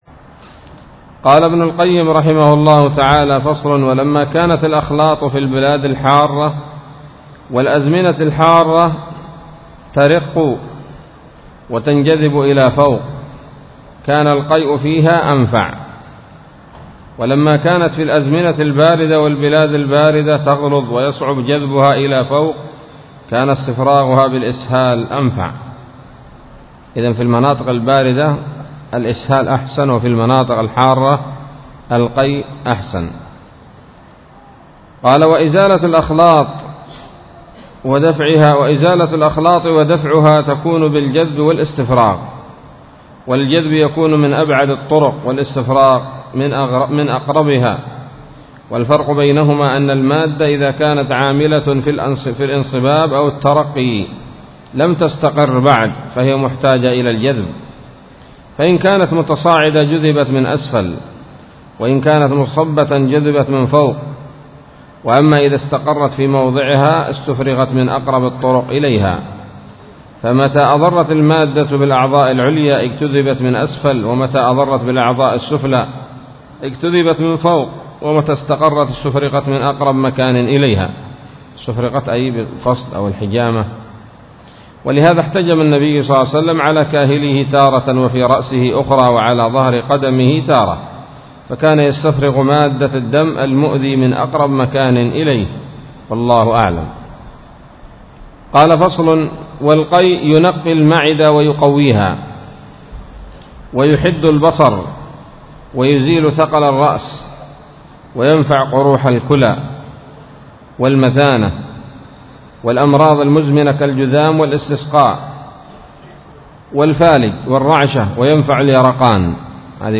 الدرس السابع والثلاثون من كتاب الطب النبوي لابن القيم